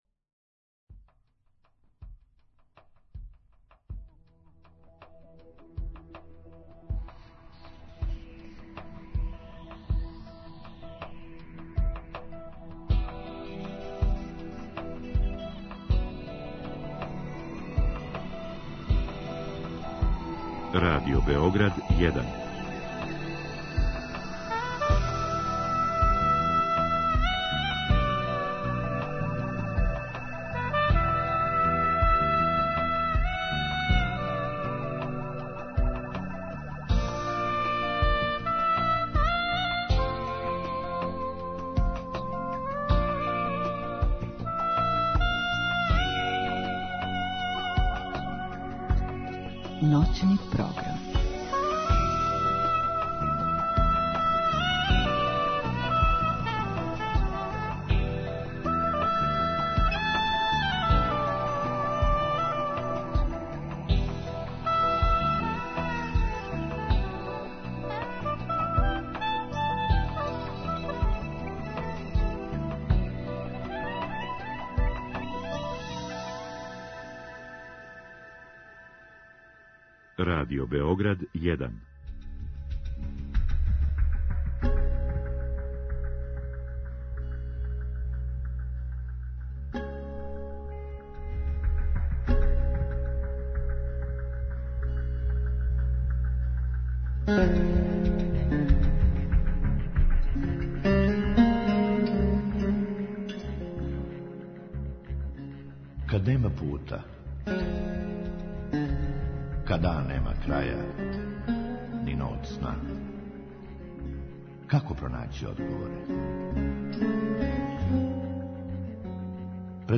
У другом сату слушаоци могу поставити питање гошћи у директном програму или путем Инстаграм странице емисије.